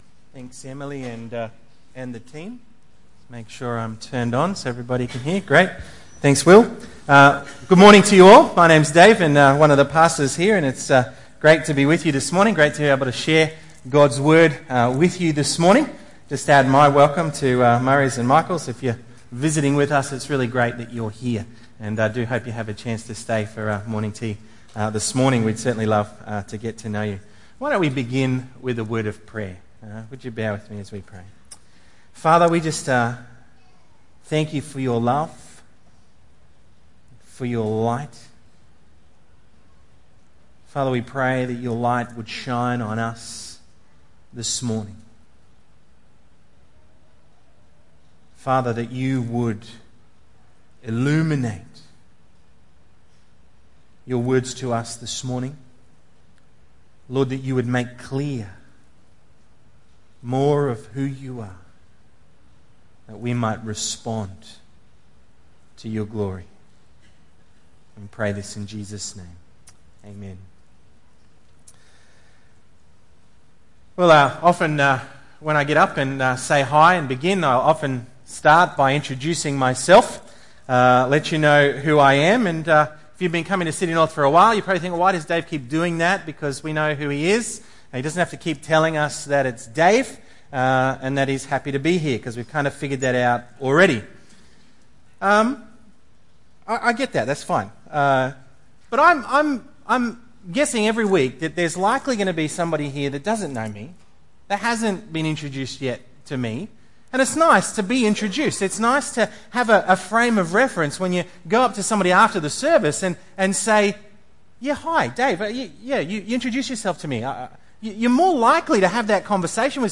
John 8:12-30 Tagged with Sunday Morning Audio (MP3) 20 MB Previous The Vineyard Workers Next Desperate Prayer